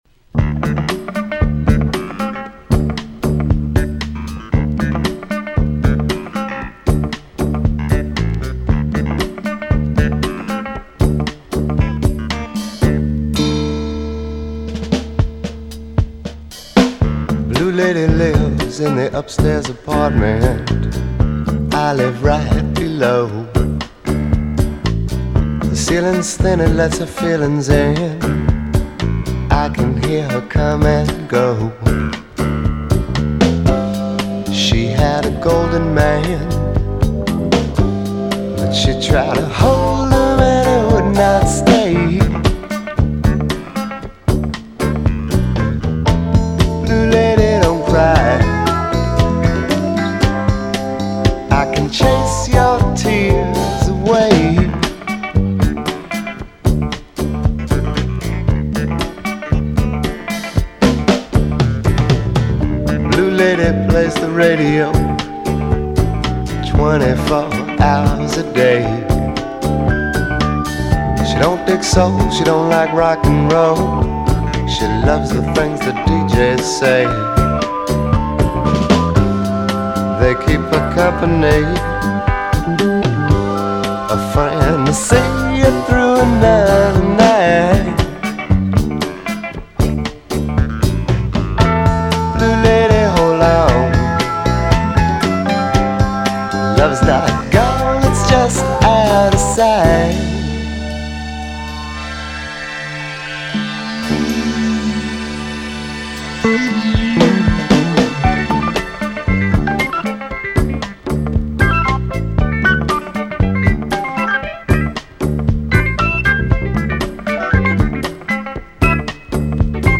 It’s catchy, and the story is amazing.